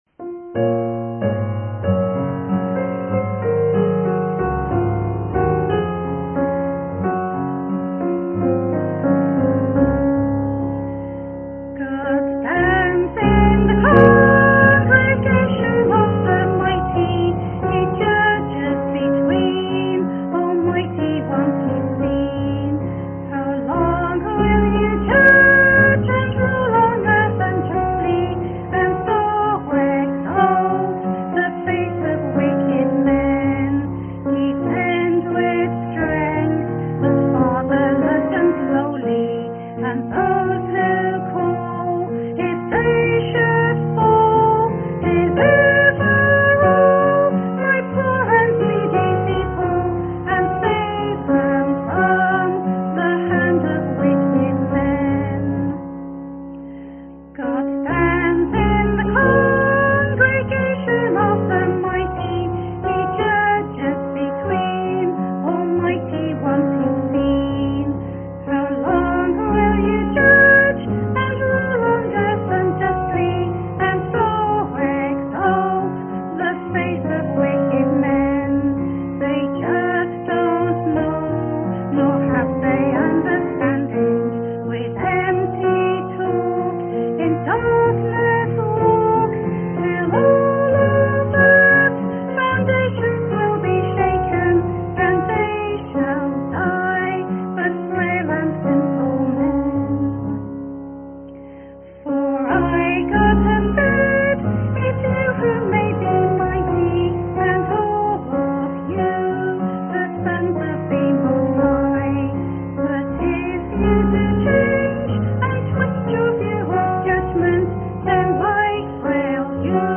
2025-10-09 (FOT Day 3)
Special Music